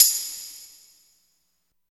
16 TAMB   -R.wav